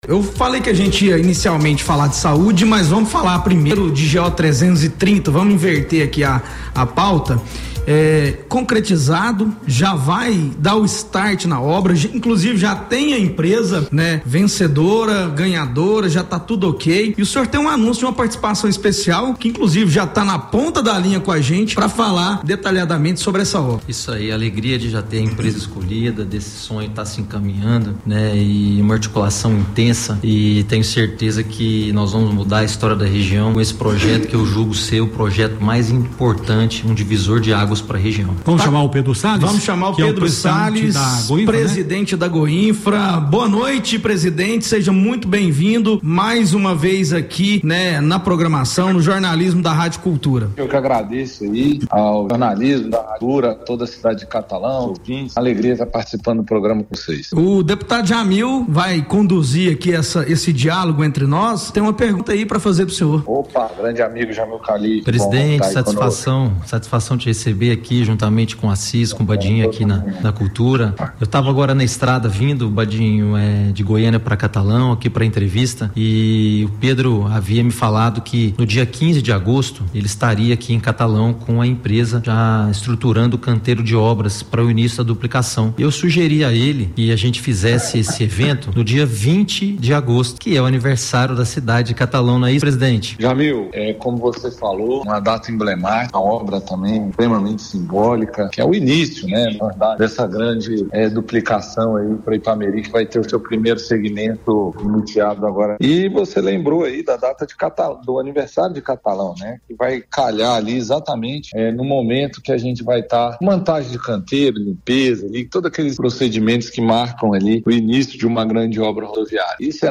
O deputado estadual Jamil Calife (UB) anunciou que as obras de duplicação da GO-330, no trecho entre Catalão e Ipameri, começam no dia 20 de agosto, data que também celebra o aniversário do município. Ele fez o anúncio durante entrevista na Rádio Cultura FM 101,1, ao lado do presidente da Agência Goiana de Infraestrutura e Transportes (Goinfra), Pedro Salles.
Ouça parte da entrevista com o deputado Jamil Calife: